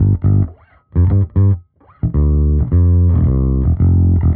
Index of /musicradar/dusty-funk-samples/Bass/110bpm
DF_JaBass_110-G.wav